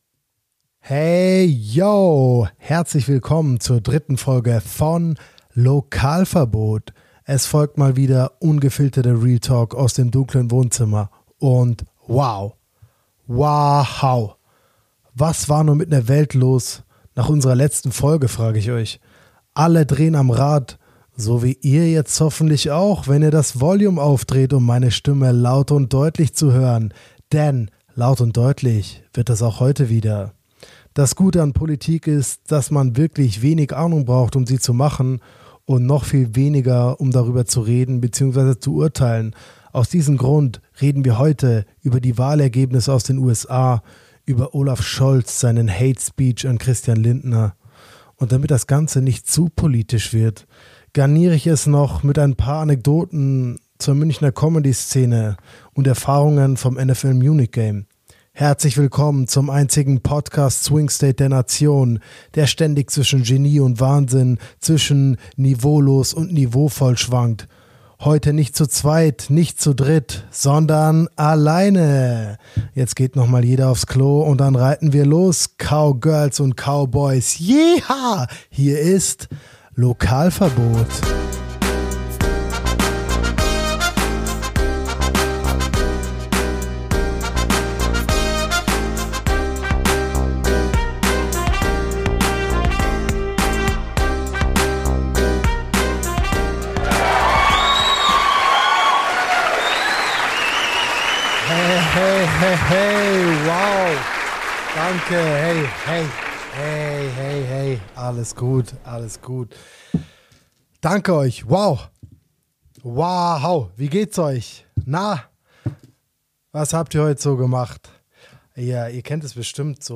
Beschreibung vor 1 Jahr Es ist mal wieder Zeit für eine Solo-Folge.